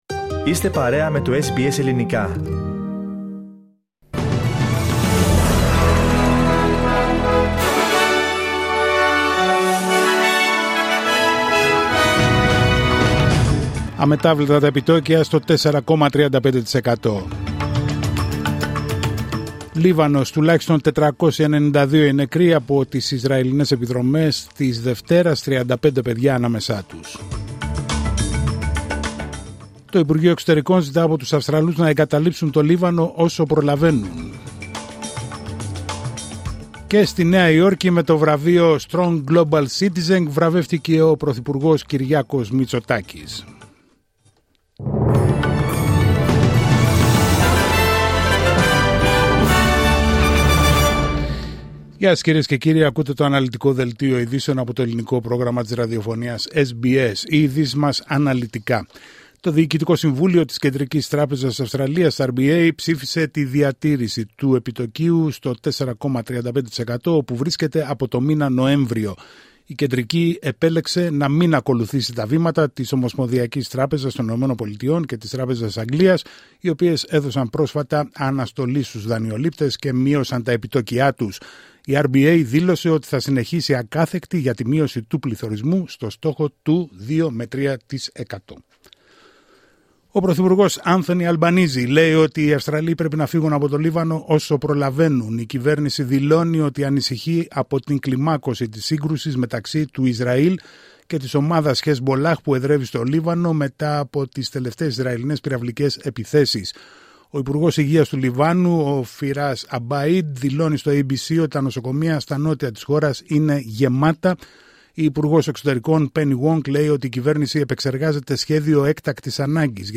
Δελτίο ειδήσεων Τρίτη 24 Σεπτεμβρίου 2024